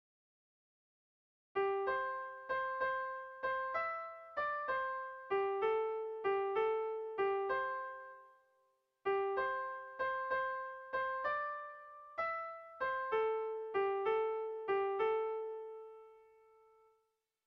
Bertso melodies - View details   To know more about this section
Erromantzea
AB